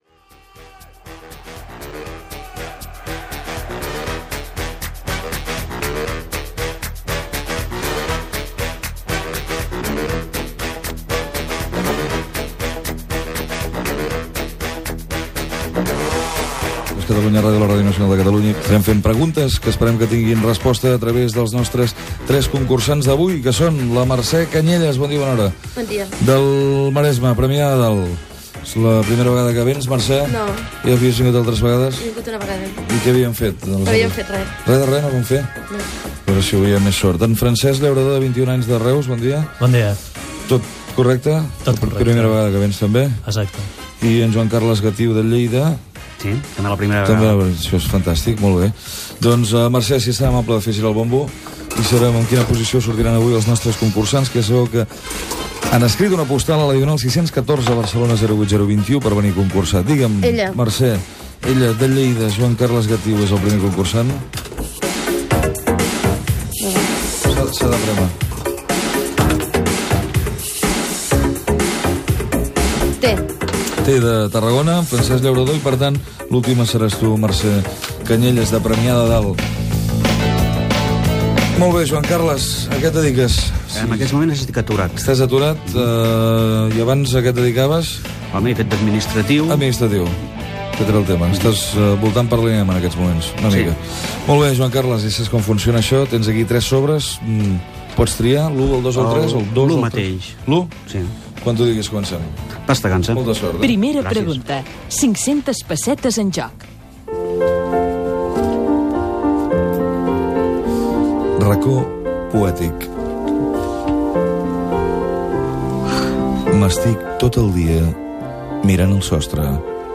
Sintonia, identificació, presentació dels concursants, sorteig de l'ordre de participació, primeres preguntes del concurs Gènere radiofònic Entreteniment